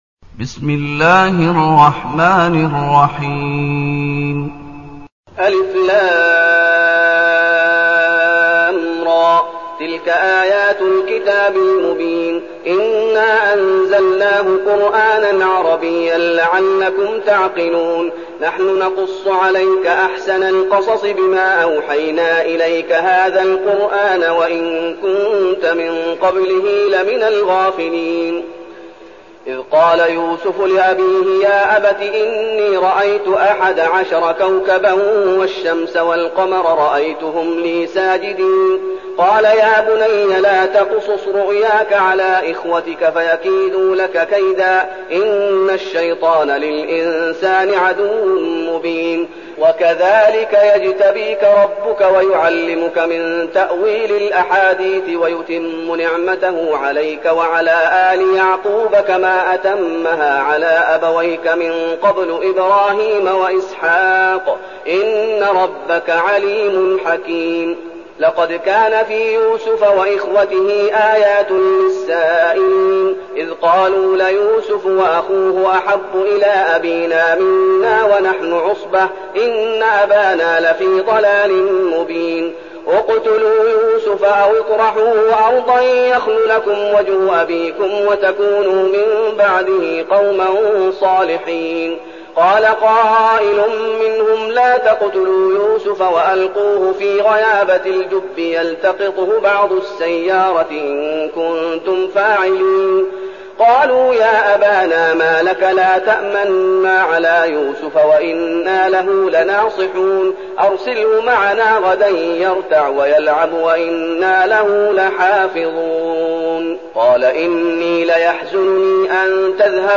تلاوة سورة يوسف بصوت الشيخ محمد أيوب
تاريخ النشر ١ محرم ١٤١٠ المكان: المسجد النبوي الشيخ: فضيلة الشيخ محمد أيوب فضيلة الشيخ محمد أيوب سورة يوسف